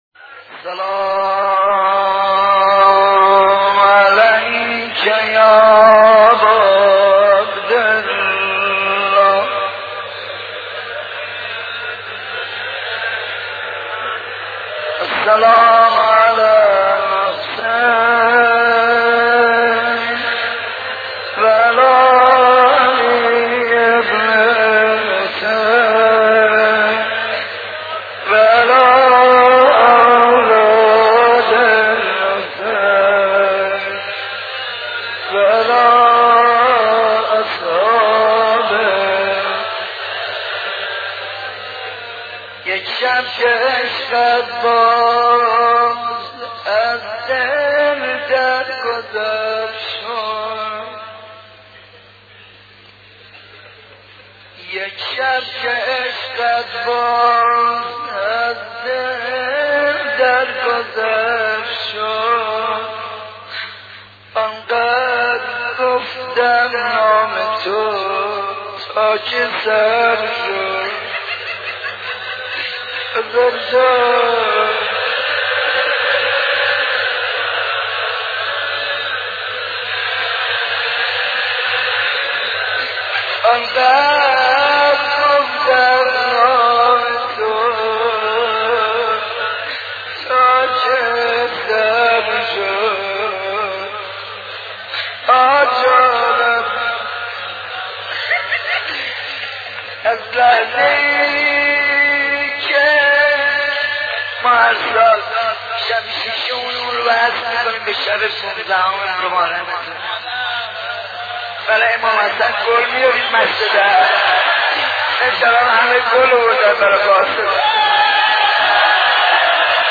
مداحی حاج منصور ارضی در شب 6 ماه محرم سال 1377 منتشر شد.
روضه‌خوانی حاج منصور ارضی مداح پیشکسوت کشورمان در شب 6 ماه محرم 1377 را می‌شنوید: